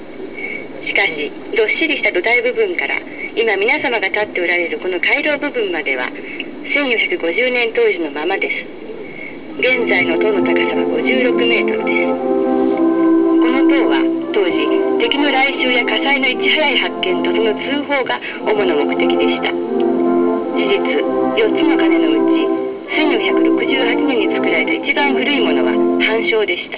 日本語音声ガイドを聞いていると、眼下の、インスブルックのシンボル
という黄金の小屋根の前広場で演奏が始まり、ぼくに本当に日本でない所にいるんだとい